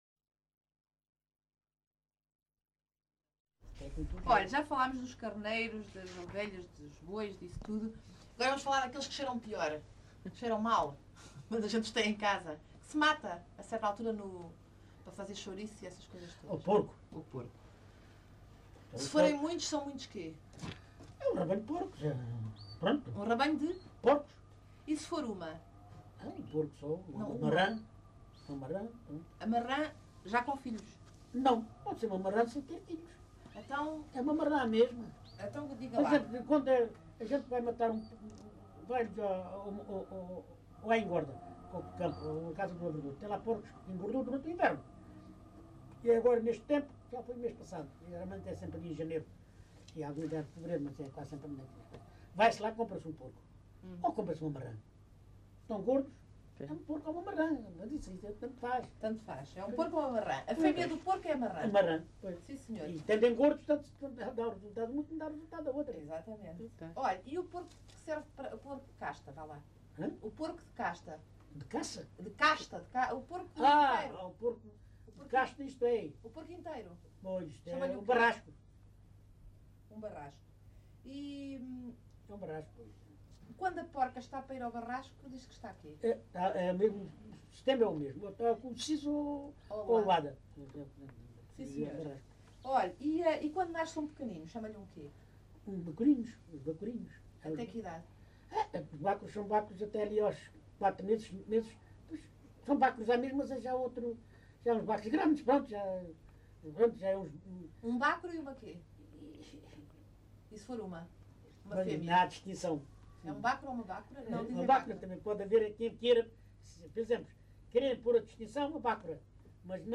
LocalidadeLavre (Montemor-o-Novo, Évora)